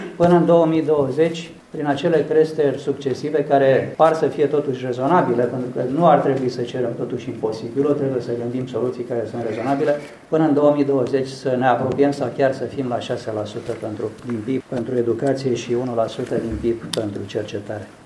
Problema subfinanțării învățământului a fost discutată la Tîrgu-Mureș, în cadrul întâlnirii Consiliului Național al Rectorilor.
Ministrul Educației, Mircea Dumitru, prezent și el la Tg.Mureș, e de părere că ţinta propusă de rectori nu este una de nerealizat: